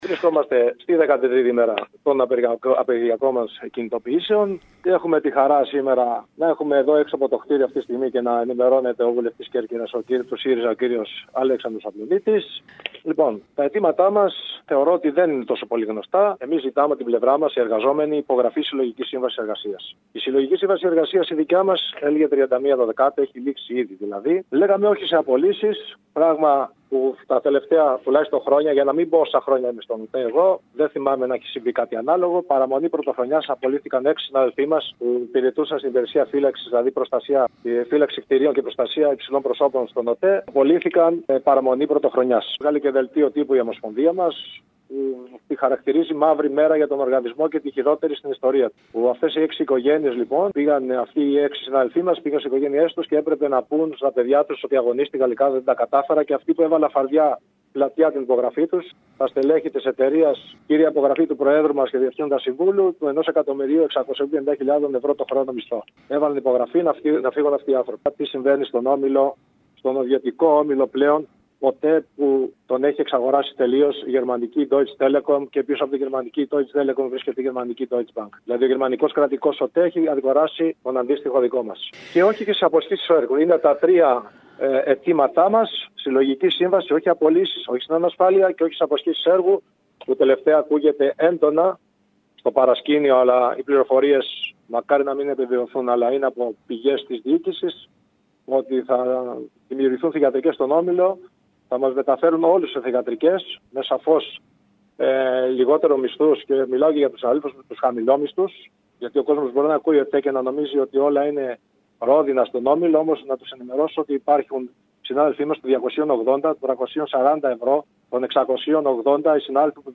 μιλώντας στην ΕΡΑ Κέρκυρας